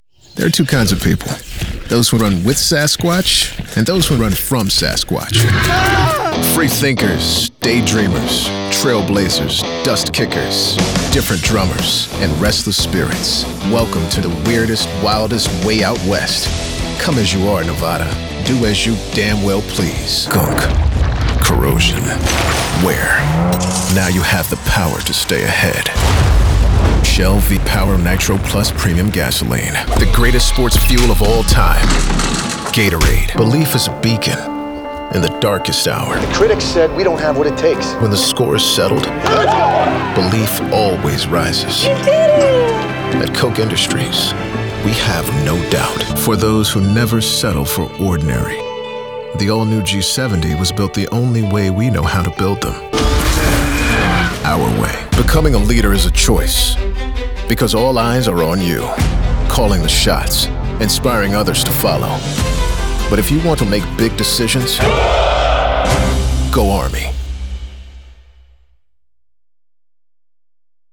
All of our contracted Talent have broadcast quality home recording studios.
Commercial Demo Video Demos Multiverse Jeep Wagoneer Ram Trucks Z Modular SRP Water Education European Speed Club Back to Voiceover Talents